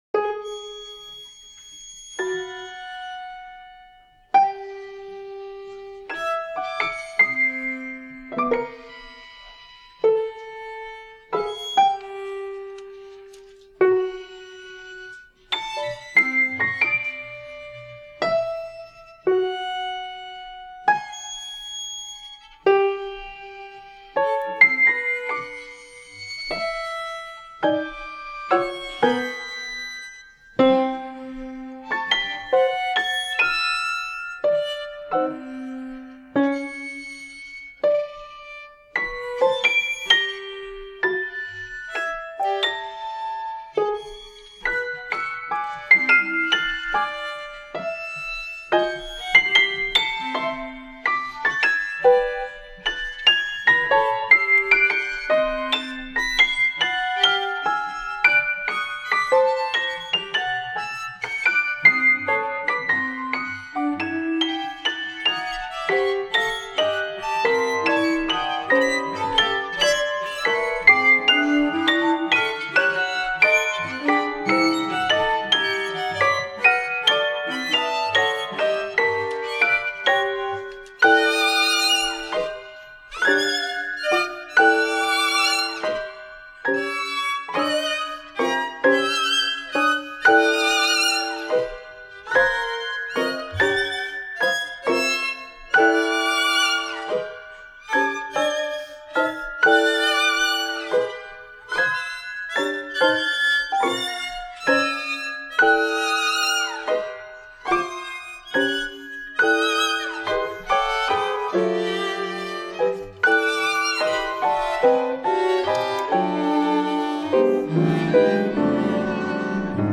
Ábaco para violín, cello y piano